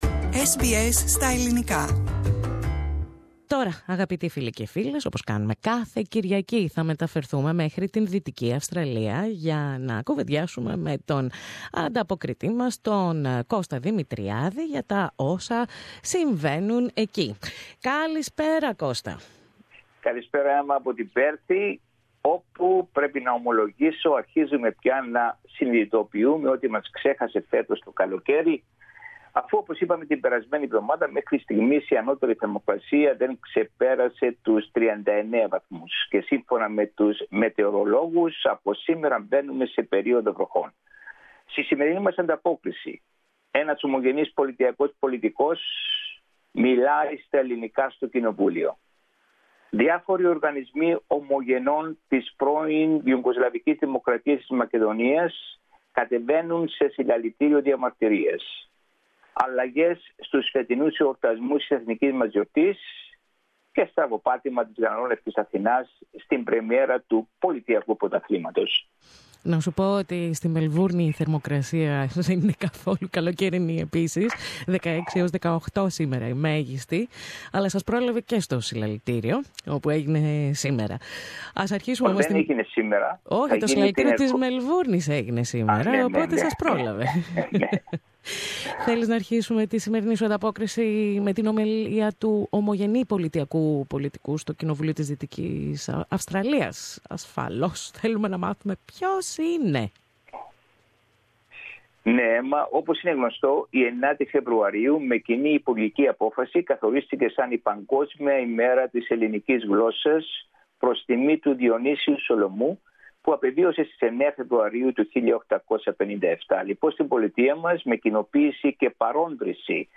Ομιλία στα ελληνικά ομογενή πολιτικού στο Κοινοβούλιο της Δυτικής Αυστραλίας